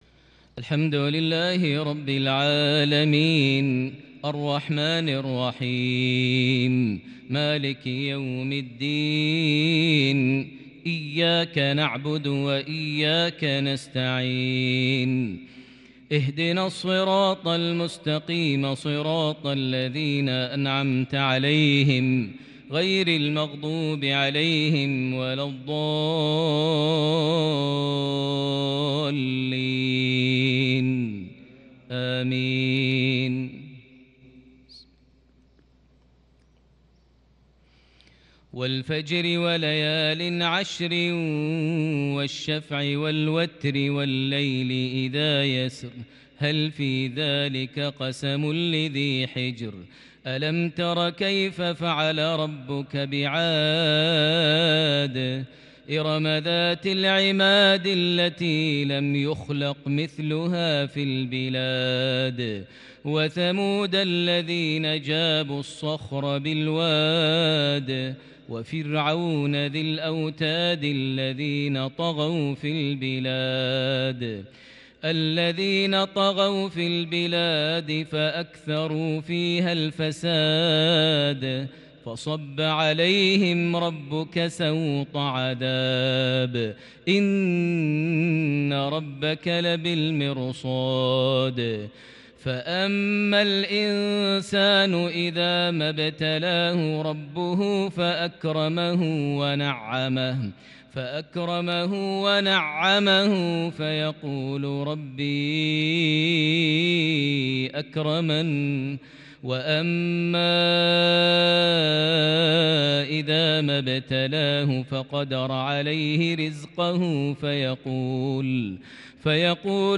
مغربية شجية لسورة الفجر 22 جمادى الآخر 1442هـ > 1442 هـ > الفروض - تلاوات ماهر المعيقلي